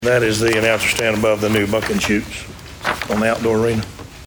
At that meeting, a resolution was signed in an effort to make improvements to the outdoor arena at the fairgrounds. District One Commissioner Anthony Hudson informs us